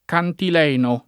cantileno [ kantil $ no ]